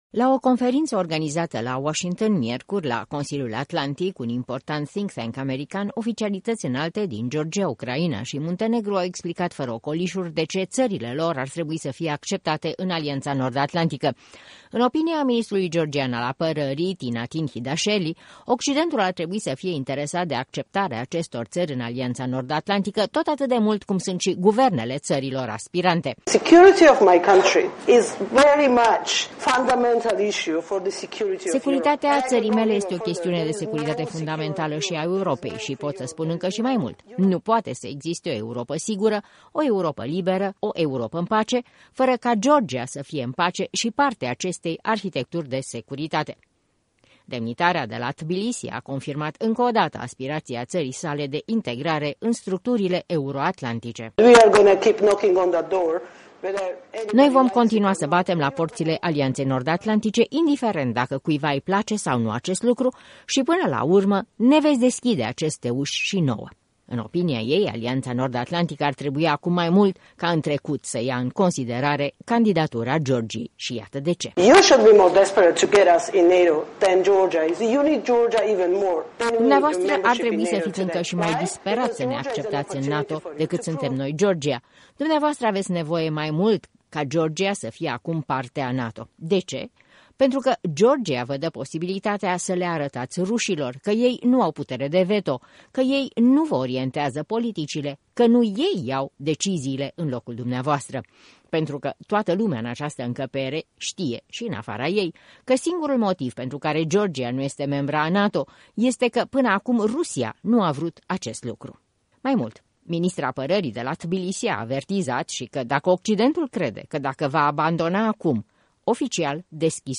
Note pe marginea unei conferințe la Consiliul Atlantic.